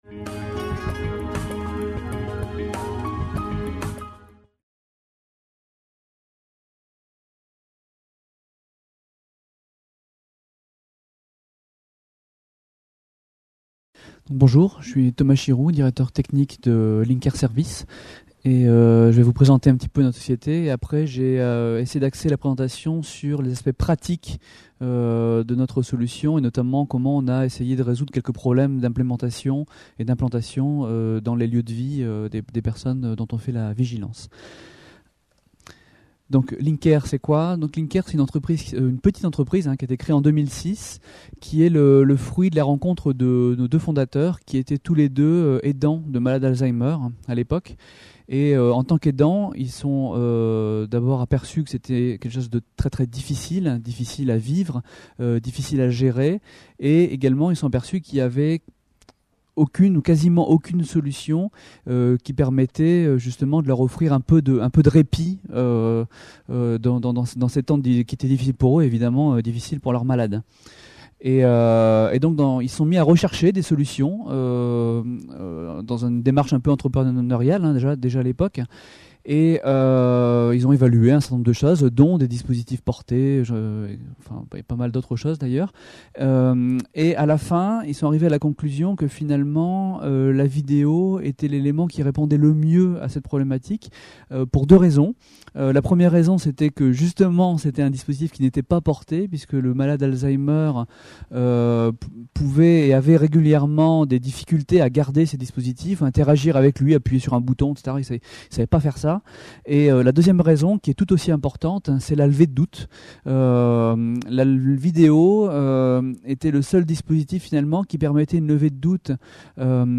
Conférence enregistrée lors du congrès international FORMATIC PARIS 2011.